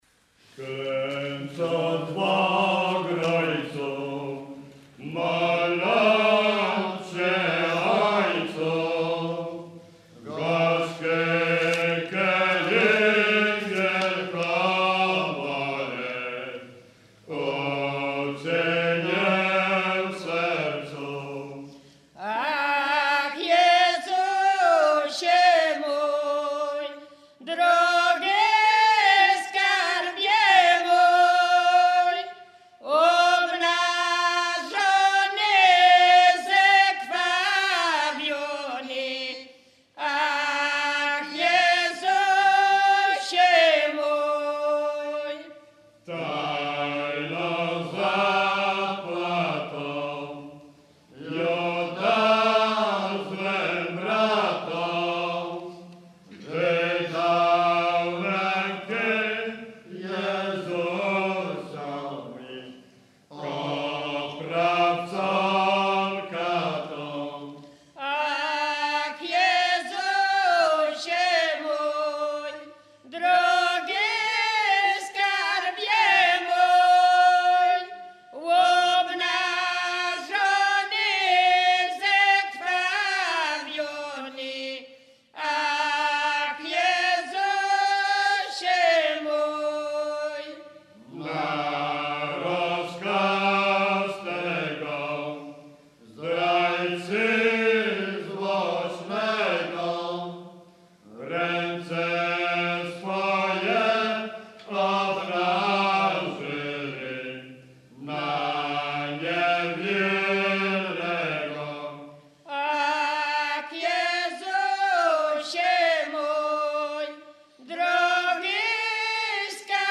Zespół śpiewaczy z Długiego
Kurpie
Wielkopostna
nabożne katolickie wielkopostne